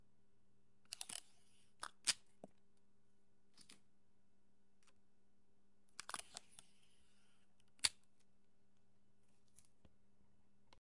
斯考特胶带
描述：透明胶带噪音 我也是一名音乐家，请在bandcamp上查看带有视频游戏音乐的页面： https
标签： 翻录 止转棒 胶水 撕裂 带材 分割 磁带 撕裂 翻录 除去 管道 剥离 噪声 粘合剂
声道立体声